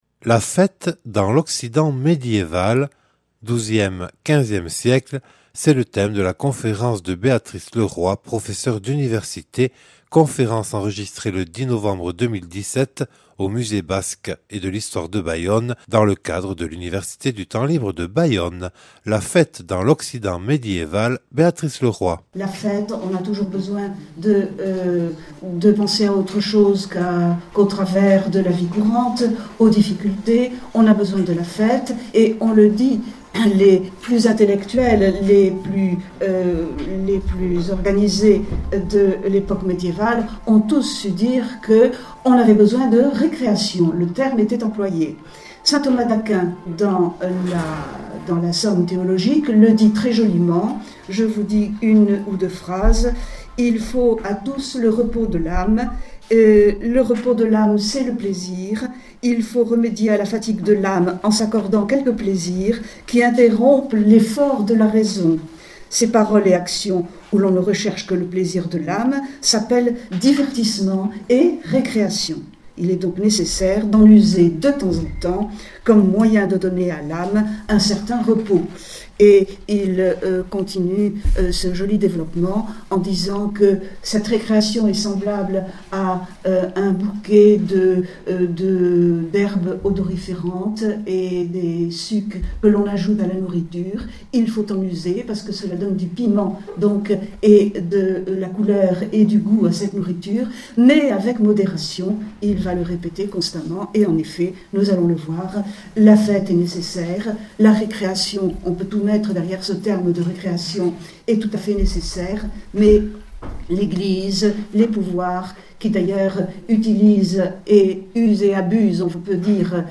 Enregistré le 10/11/2017 lors de l’Université du Temps Libre de Bayonne